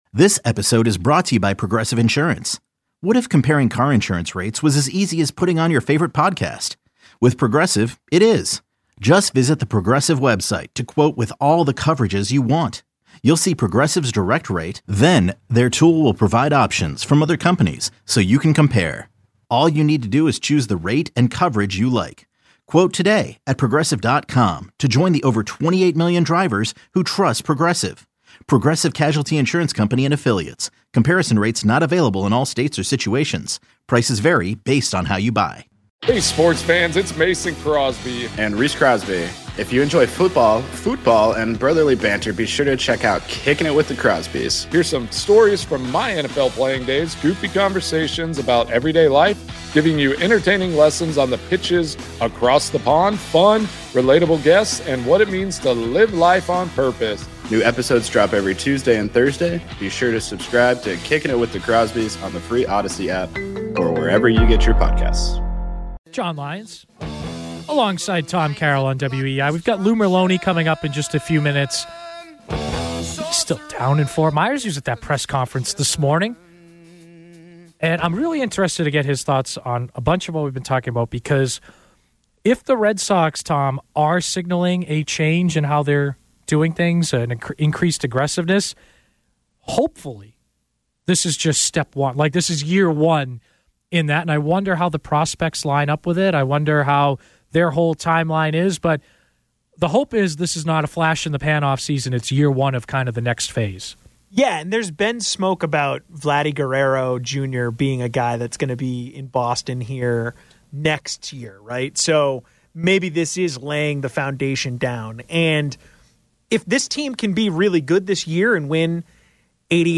Red Sox-related audio from WEEI shows and podcasts, including postgame interviews.